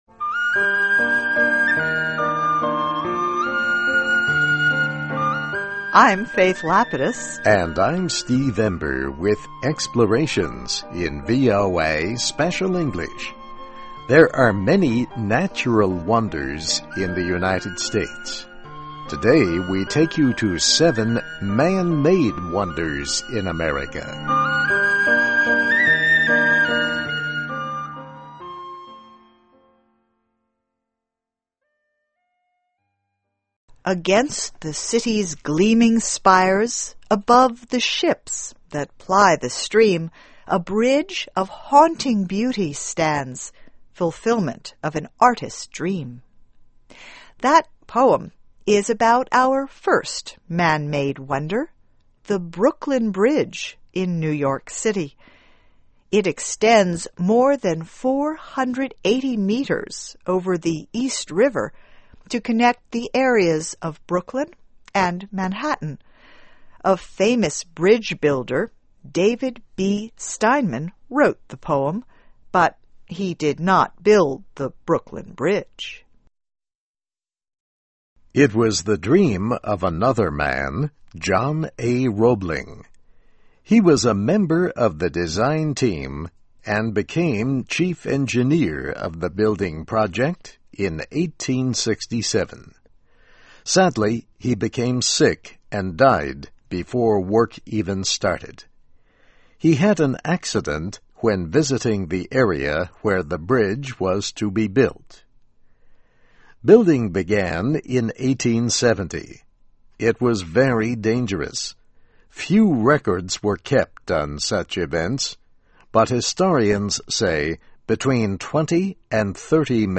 Places: Visiting 7 Man-Made Wonders in the US (VOA Special English 2009-03-03)
Listen and Read Along - Text with Audio - For ESL Students - For Learning English